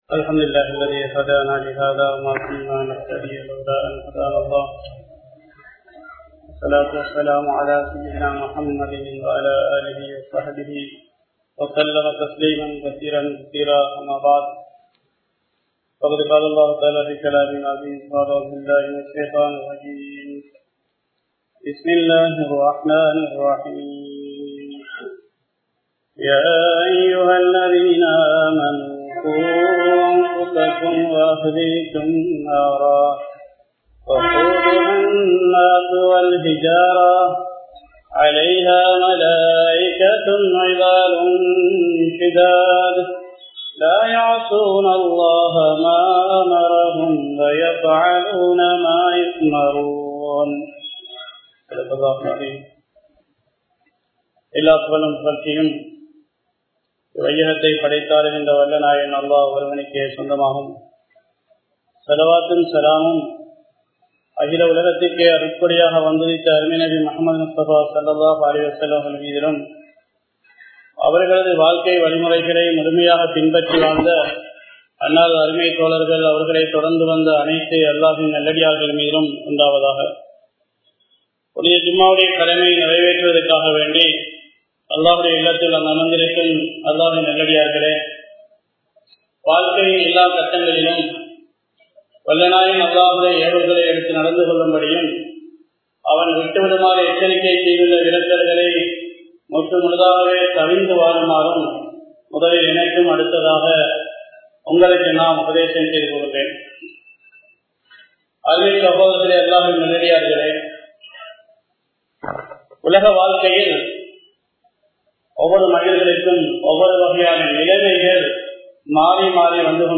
Vetriyaalarkal Yaar?(வெற்றியாளர்கள் யார்?) | Audio Bayans | All Ceylon Muslim Youth Community | Addalaichenai